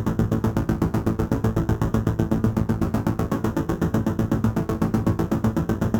Index of /musicradar/dystopian-drone-samples/Tempo Loops/120bpm
DD_TempoDroneC_120-A.wav